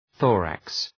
Προφορά
{‘ɵɔ:ræks}